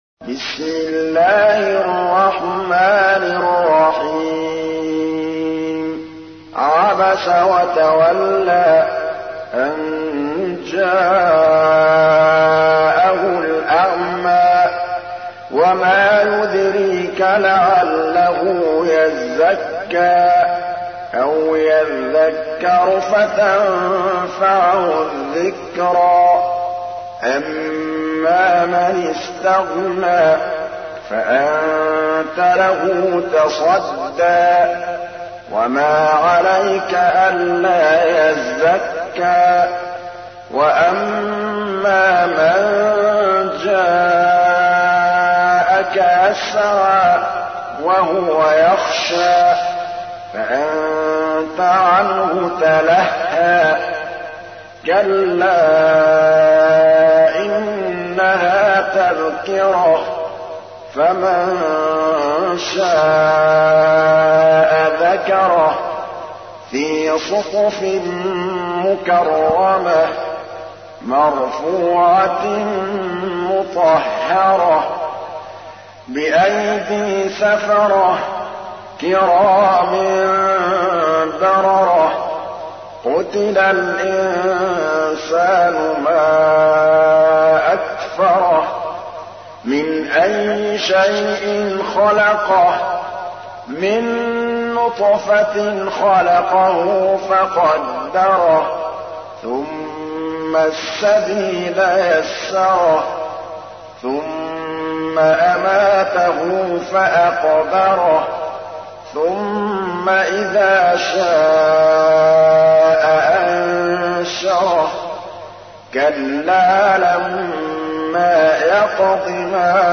تحميل : 80. سورة عبس / القارئ محمود الطبلاوي / القرآن الكريم / موقع يا حسين